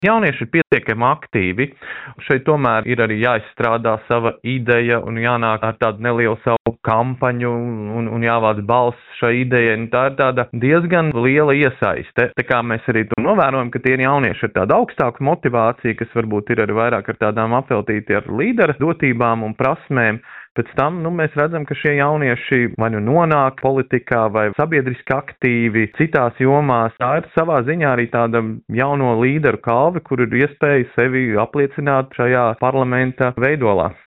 intervijā Skonto mediju grupai (SMG)